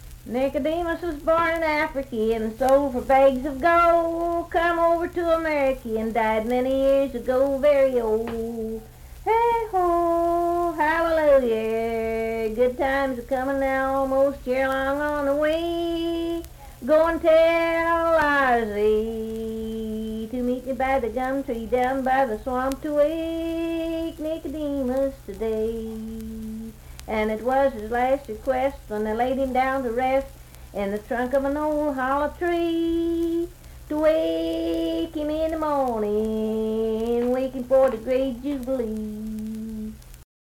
Unaccompanied vocal music
Minstrel, Blackface, and African-American Songs
Voice (sung)
Richwood (W. Va.), Nicholas County (W. Va.)